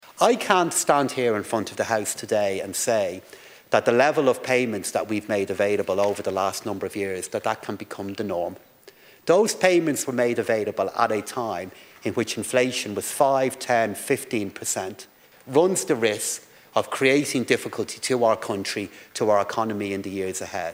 Finance Minister Paschal Donohoe says the country needs to be weaned away from such payments: